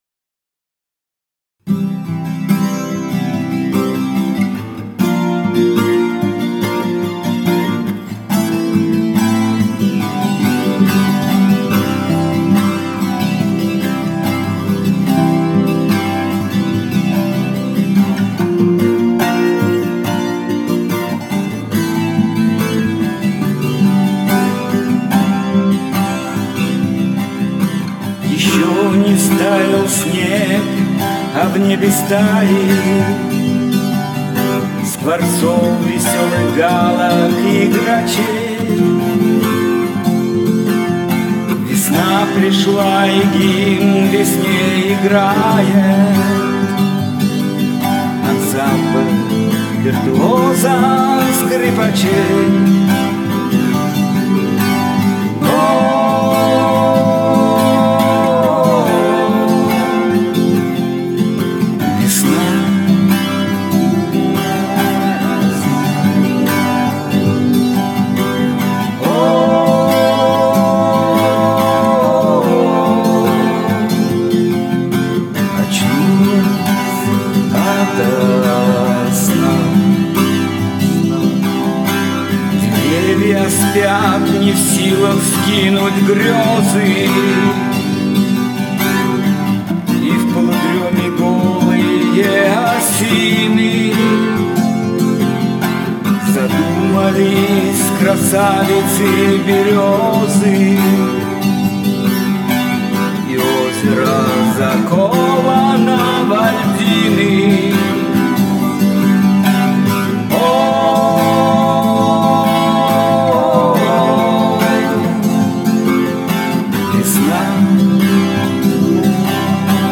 гитара